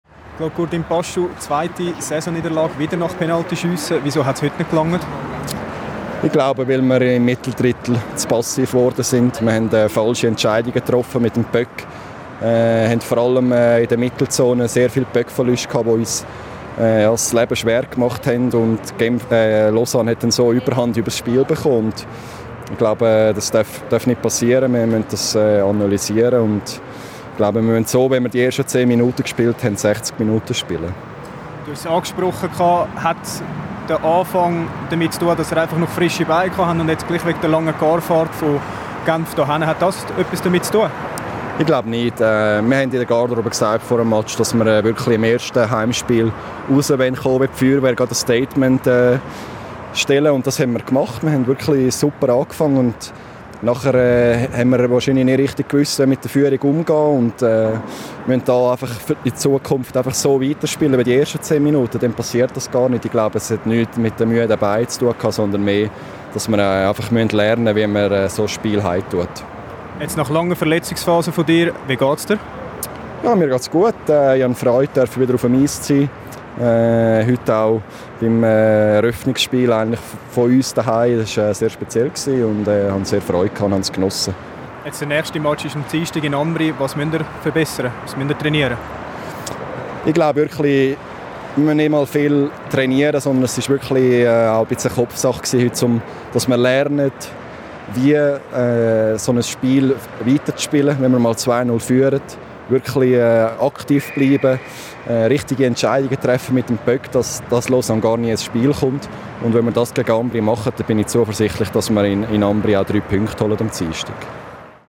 Im Interview nach dem ersten Heimspiel